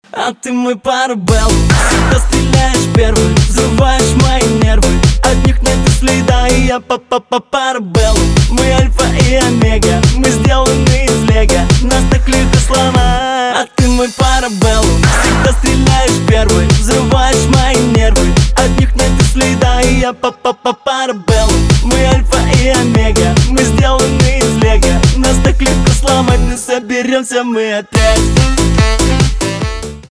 поп
позитивные , битовые , саксофон , басы